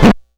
Record Noises
Record_Drag_1.aif